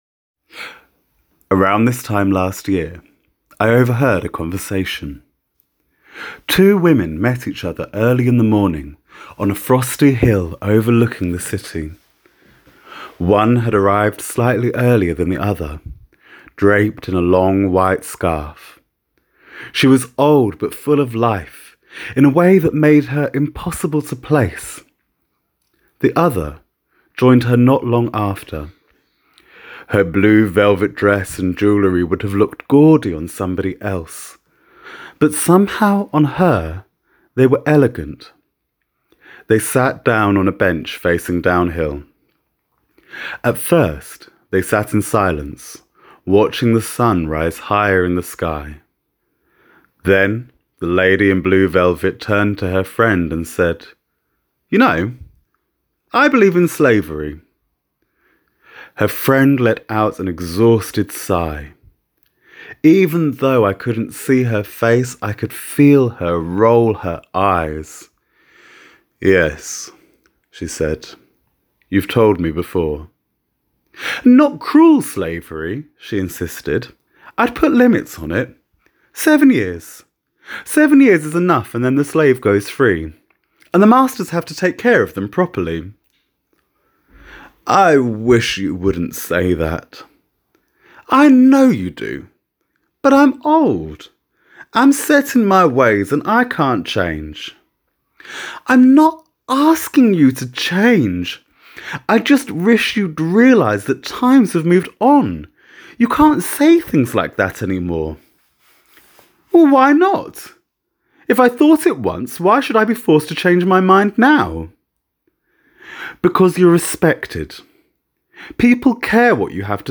sermon · story · theology · torah